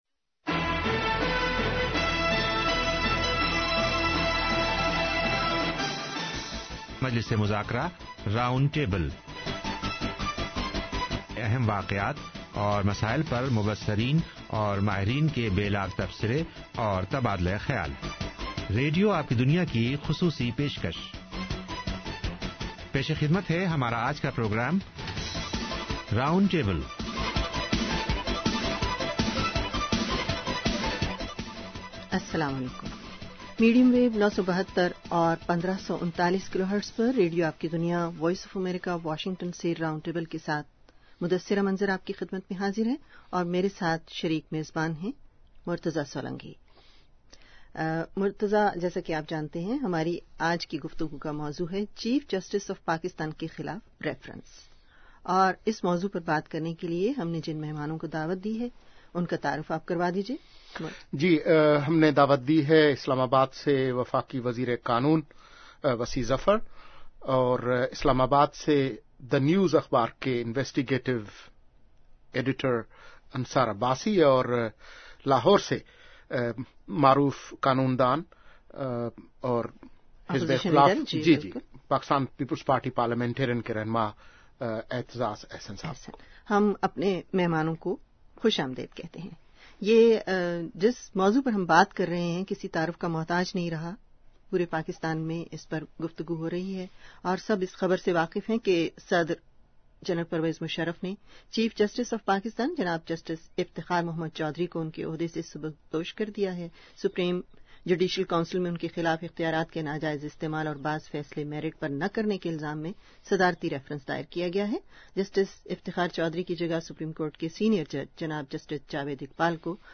yeah you can listen to the entire 40+ min VOA rountable audio here